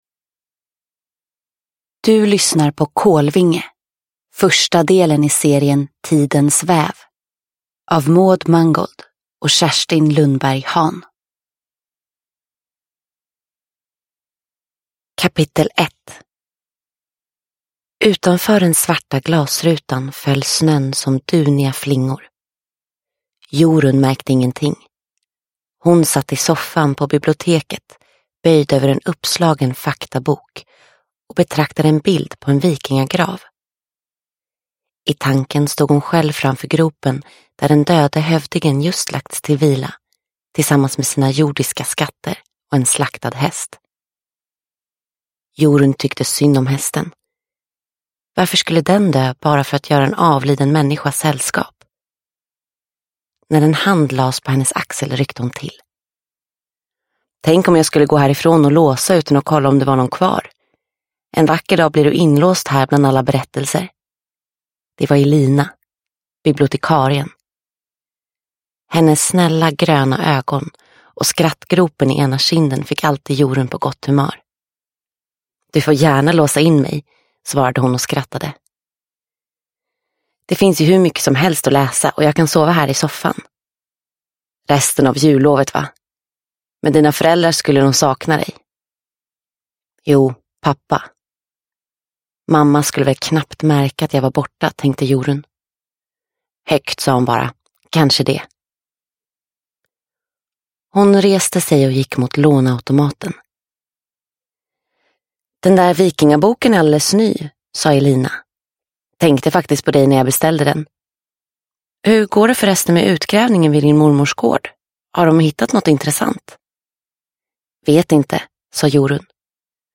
Kolvinge – Ljudbok – Laddas ner